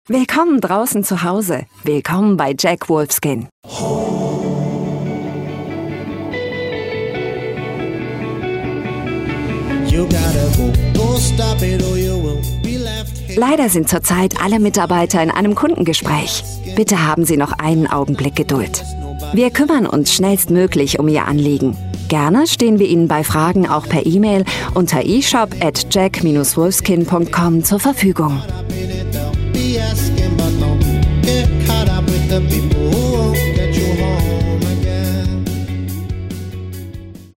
Native voices